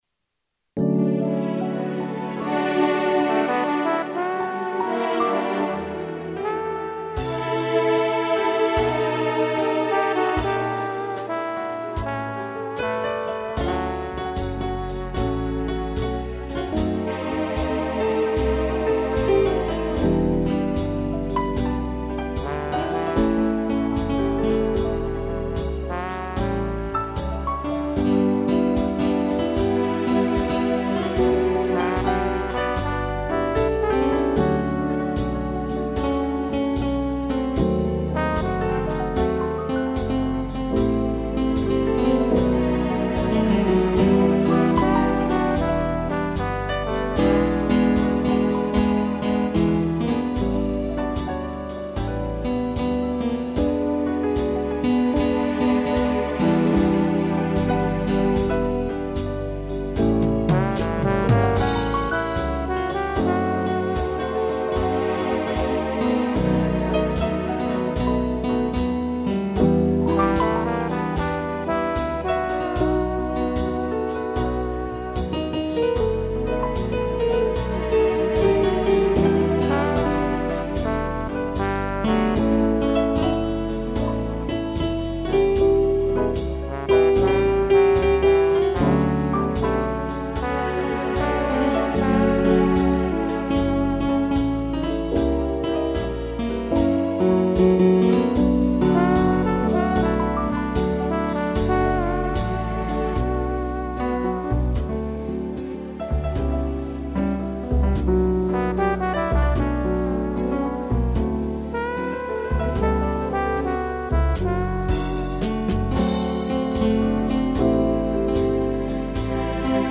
piano e trombone
(instrumental)